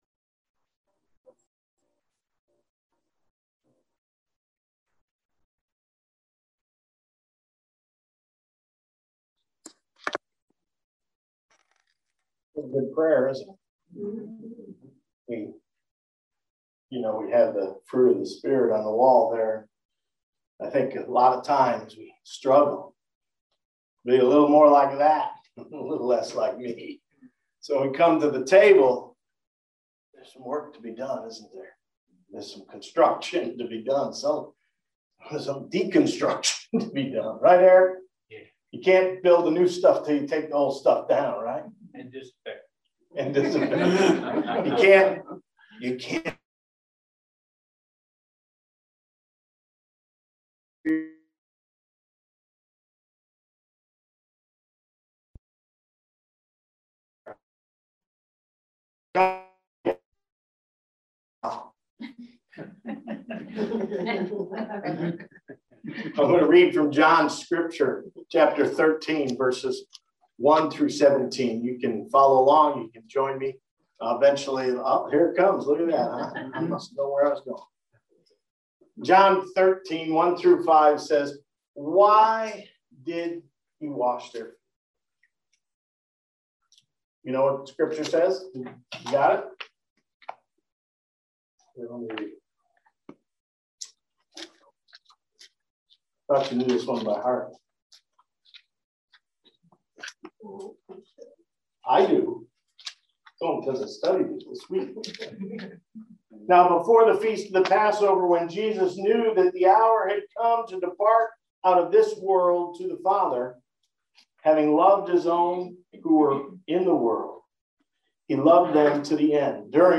Service Type: Sunday Worship